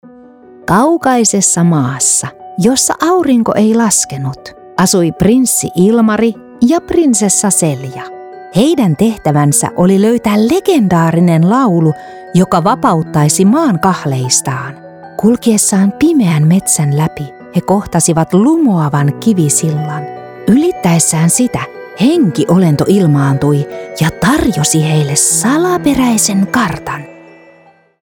Audiobooks
I record in a professional studio environment with professional recording equipment.
ConversationalTrustworthyEnergeticNeutralExpressive